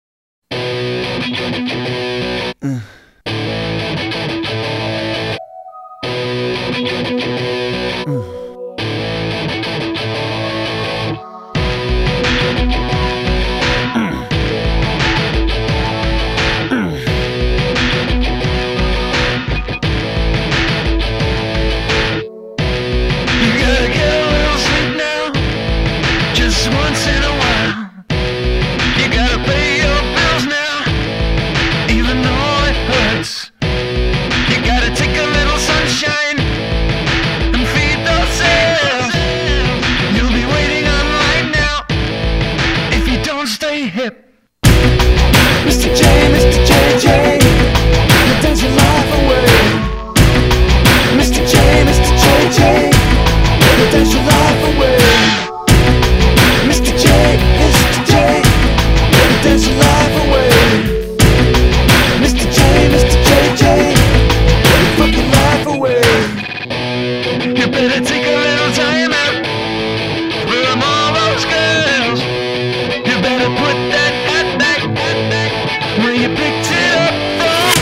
remix version